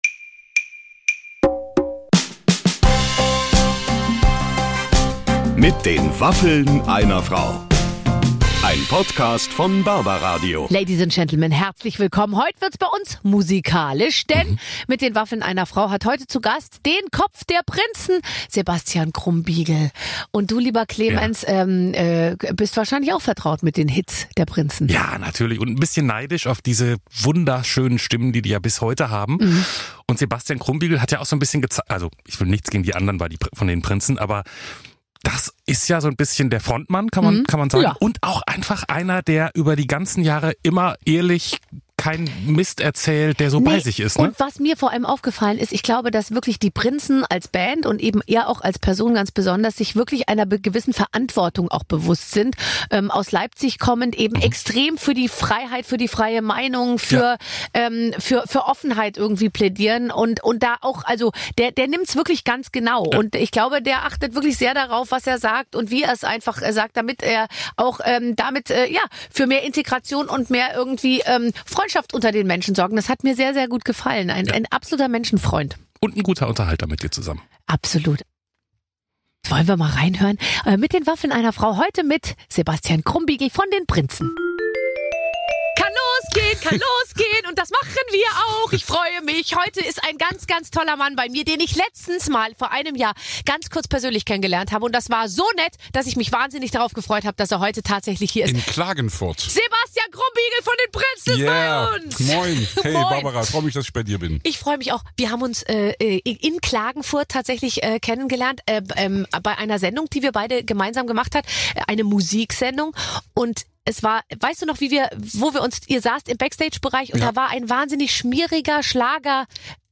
Den einen von den Prinzen: Sebastian Krumbiegel hat sich bei uns eingefunden und plaudert fleißig über Alkohol, den er gerade wenig trinkt, seine Aggressionen beim Autofahren und seine Zeit als Musiker.